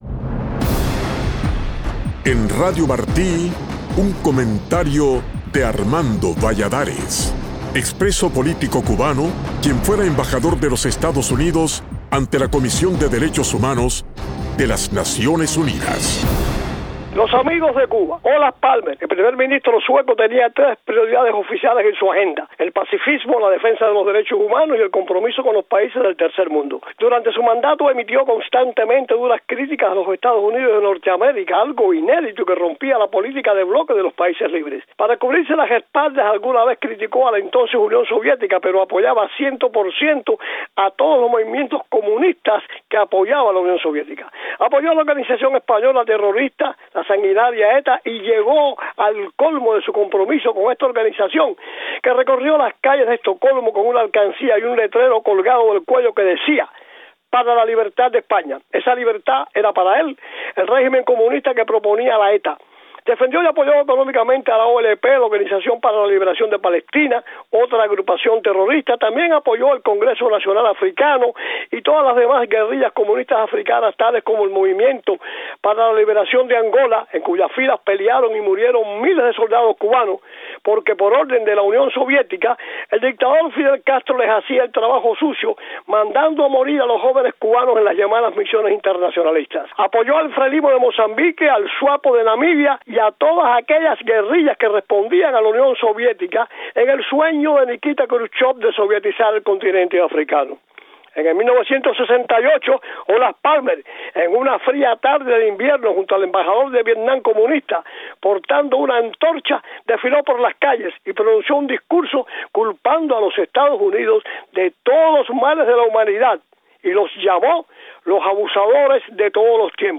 Embajador Valladares: Mi opinión
El primer ministro sueco Olof Palme tenía tres prioridades en su agenda: el pacifismo, los derechos humanos y el compromiso con los países del tercer mundo. El exembajador ante ONU, Armando Valladares, nos cuenta en su comentario cómo el estadista puso por delante a los regímenes comunistas apoyados por la URSS.